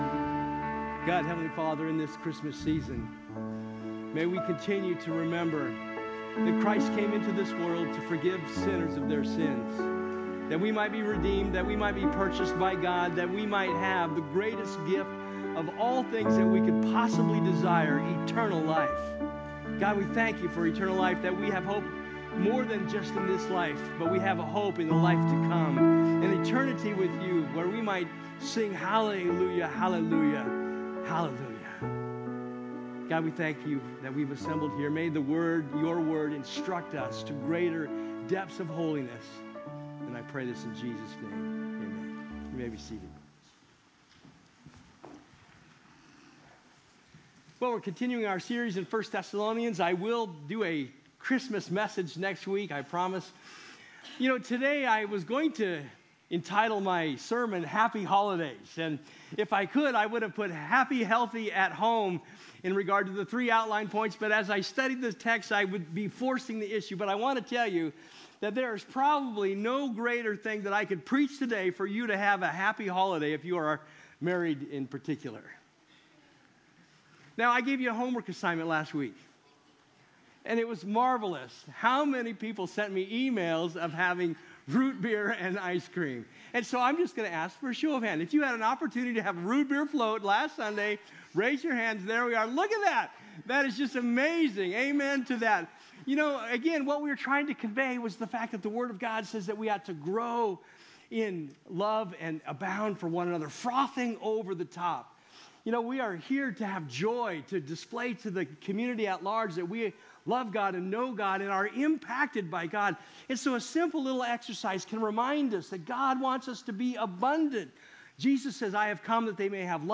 Sermon Archive | Avondale Bible Church